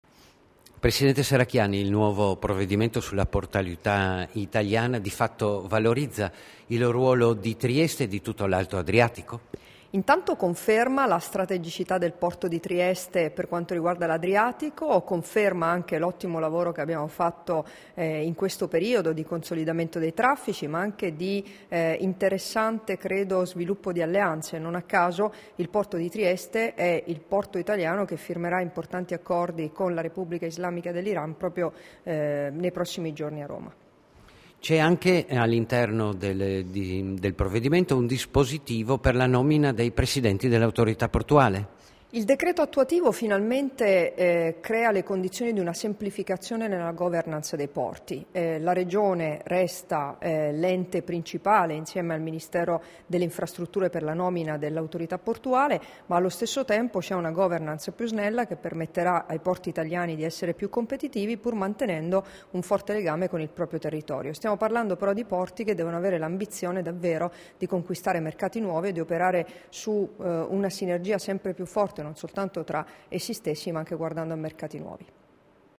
Dichiarazioni di Debora Serracchiani (Formato MP3) [1213KB]
sull'approvazione, in Consiglio dei Ministri, del decreto legislativo di Riforma della portualità nazionale, rilasciate a Trieste il 21 gennaio 2016